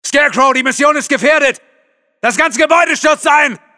First-person shooter